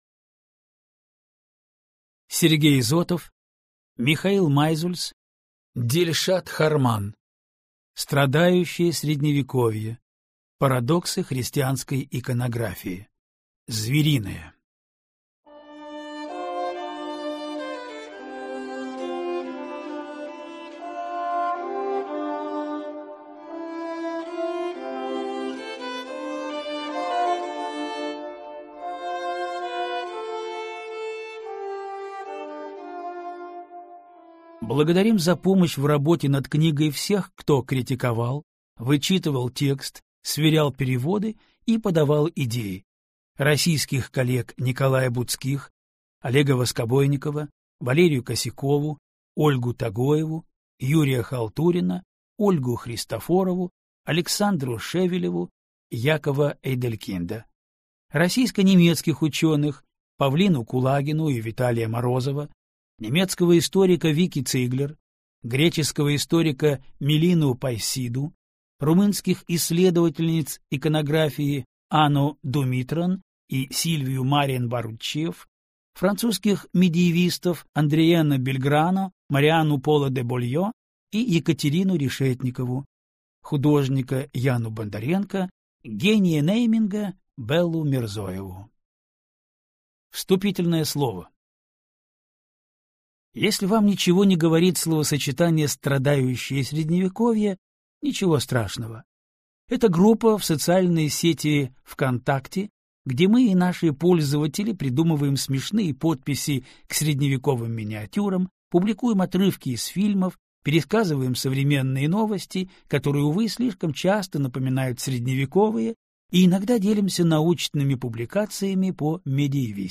Аудиокнига Страдающее Средневековье. Парадоксы христианской иконографии. Звериное | Библиотека аудиокниг